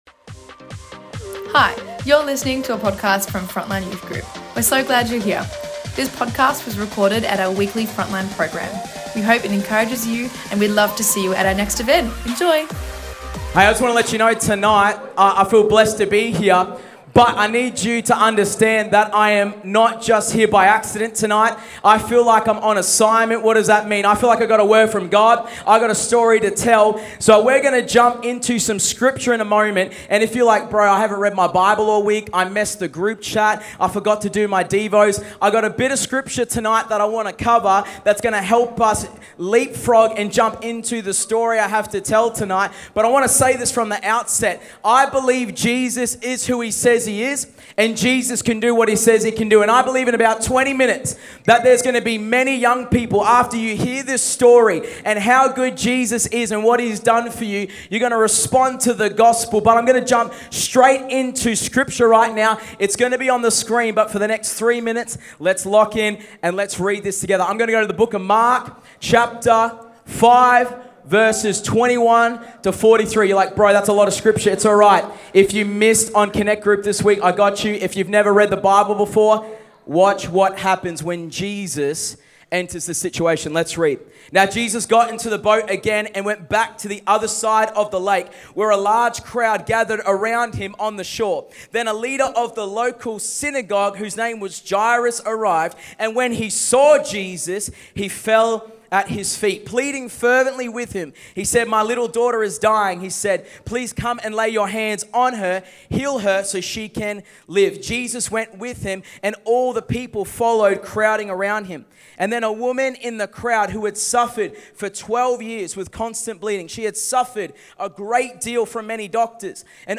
On this night we had 450 youth from all over the Eastern suburbs join us for our Undivided Youth Alive night!